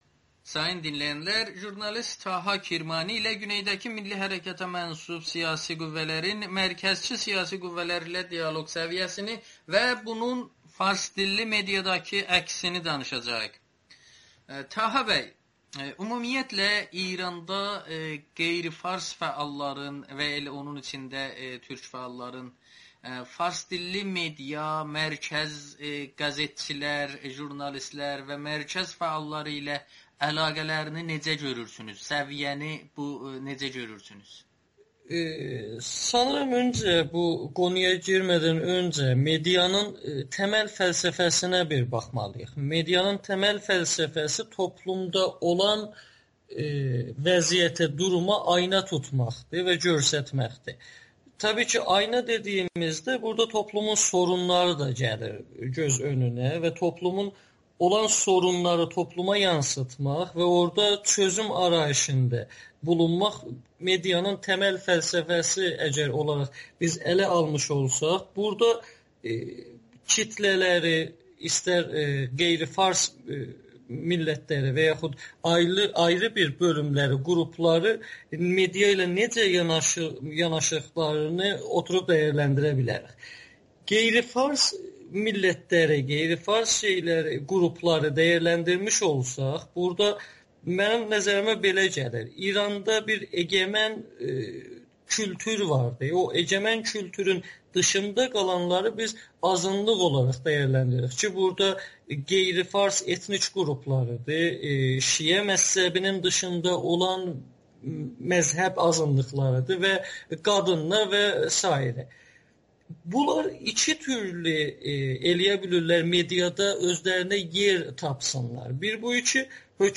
Milli Hərəkat qüvvələri medianın önəmini gec başa düşdü [Audio-Müsahibə]